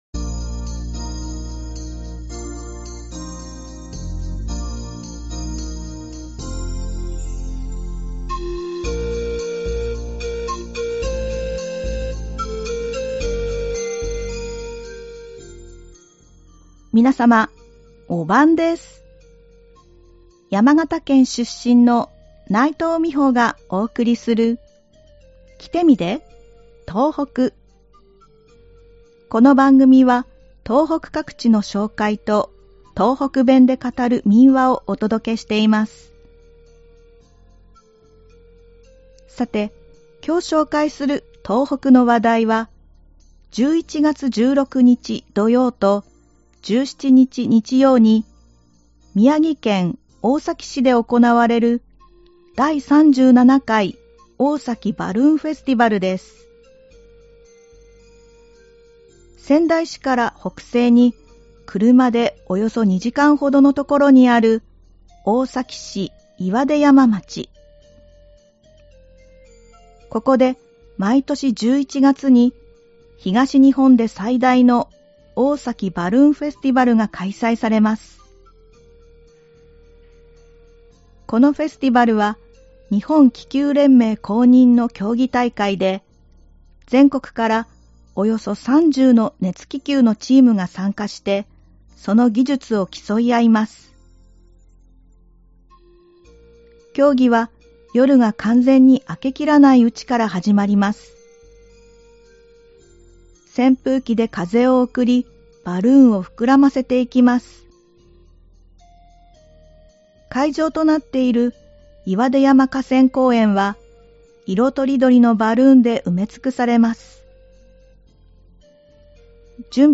この番組は東北各地の紹介と、東北弁で語る民話をお届けしています。 さて、今日紹介する東北の話題は、11月16日土曜と17日 日曜に宮城県大崎市で行われる「第37回大崎バルーンフェスティバル」です。
ではここから、東北弁で語る民話をお送りします。今回は宮城県で語られていた民話「わしの卵」です。